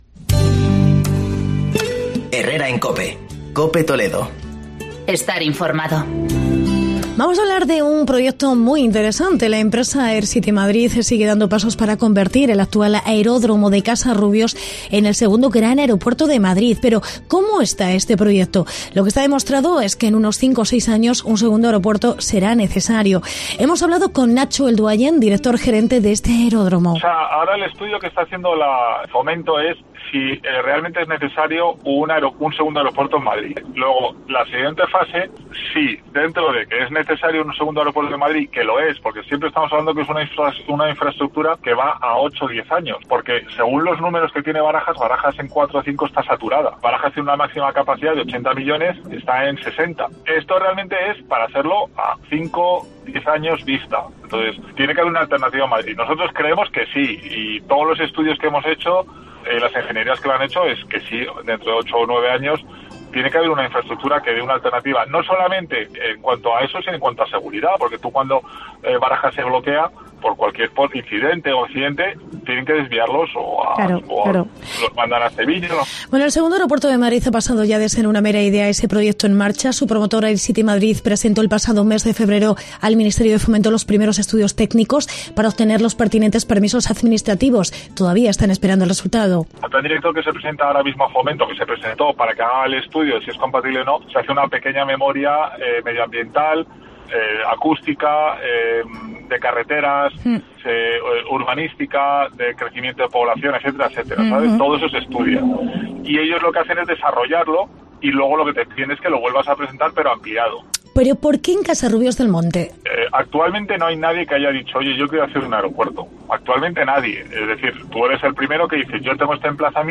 Escucha la entrevista y descubre por qué Casarrubios es la ubicación ideal para este aeropuerto y las posibilidades, en caso de no salir adelante el proyecto, de convertirse en un aeropuerto para ejecutivos.